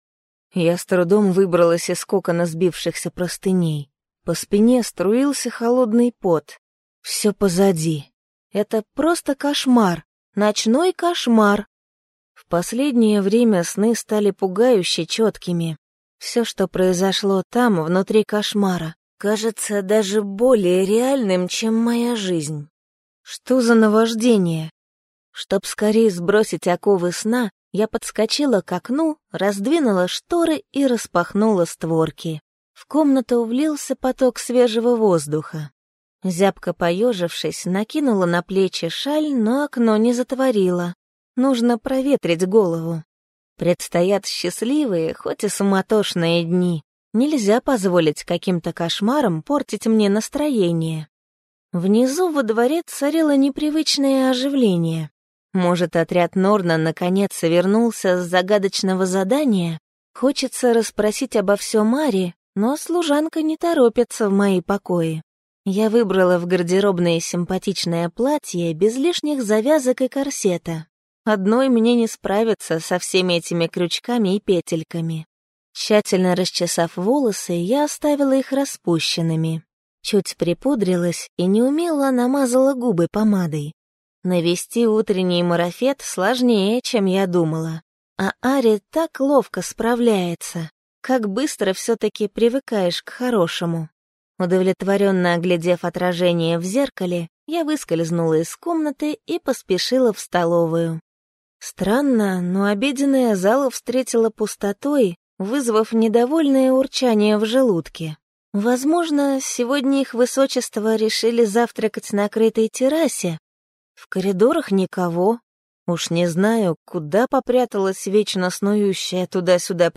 Аудиокнига Закон Благодарности. Ведьма | Библиотека аудиокниг